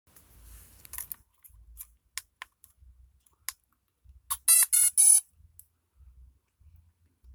HS175D DRONE'S POWER UP SOUND